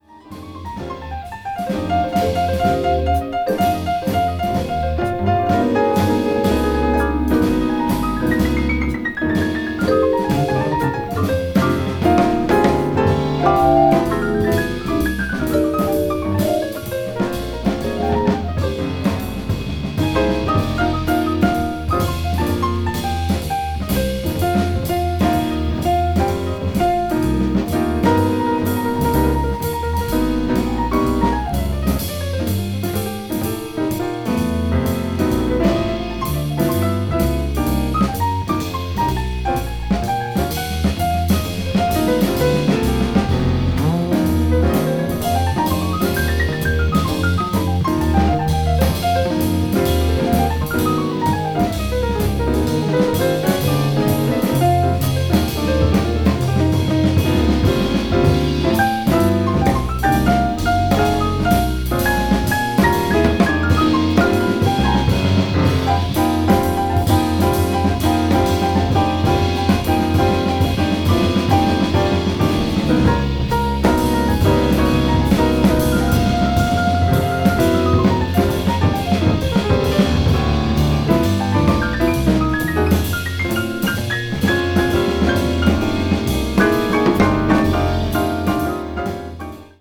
media : EX/EX(わずかにチリノイズが入る箇所あり)
ゆったりとリラックスしてお聴きいただけます。
modern jazz   post bop